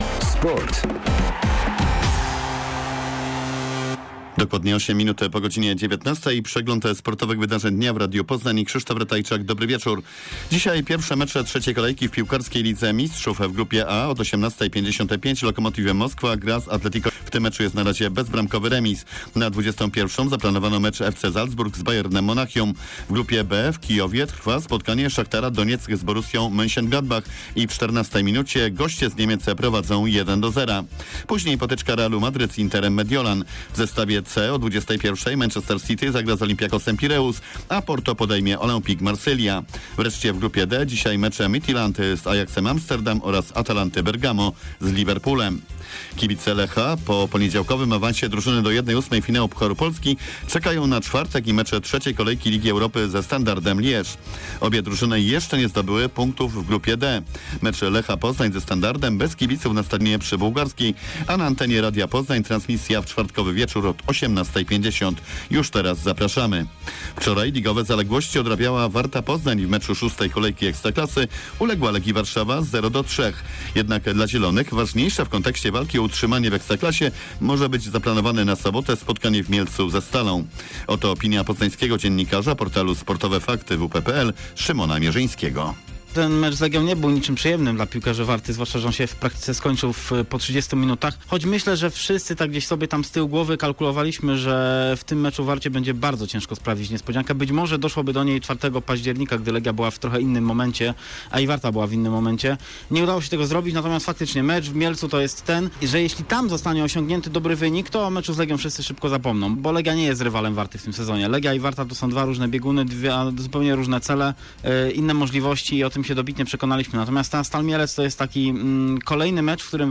03.11. SERWIS SPORTOWY GODZ. 19:05